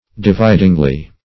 dividingly - definition of dividingly - synonyms, pronunciation, spelling from Free Dictionary Search Result for " dividingly" : The Collaborative International Dictionary of English v.0.48: Dividingly \Di*vid"ing*ly\, adv.